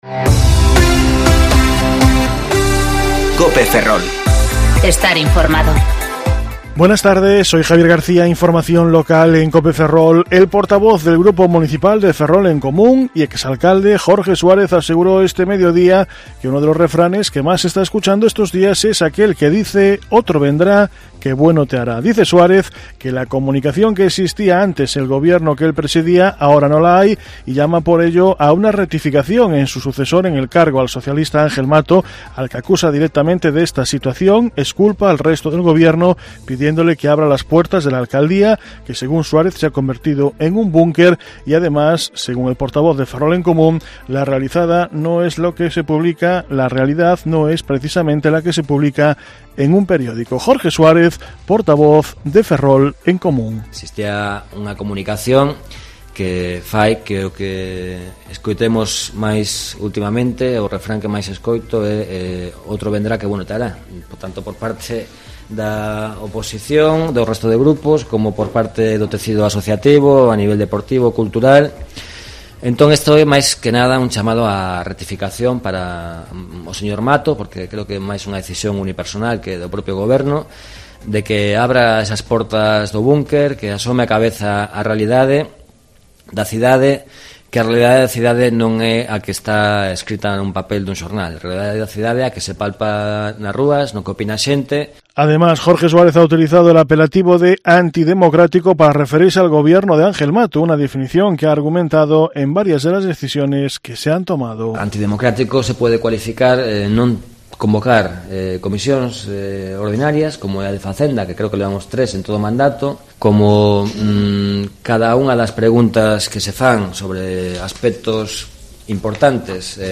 Informativo Mediodía Cope Ferrol 6/11/2019 (De 14.20 a 14.30 horas)